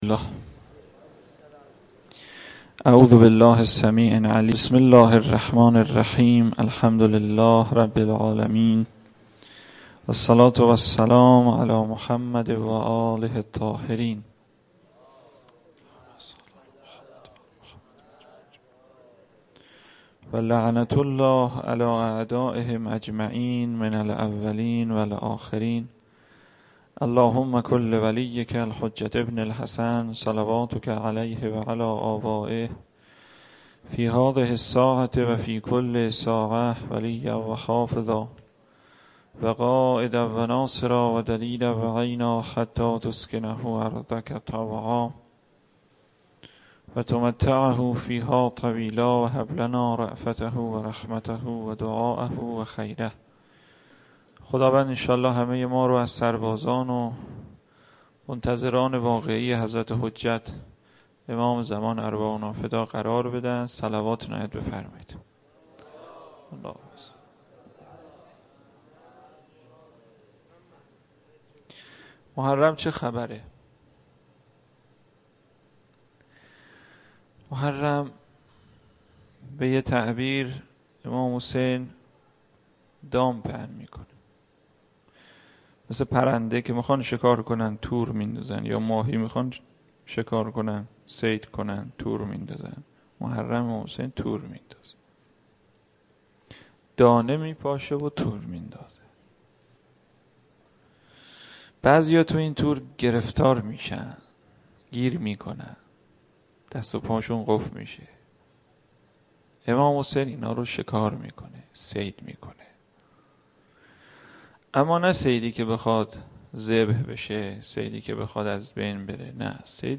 سخنرانی -روضه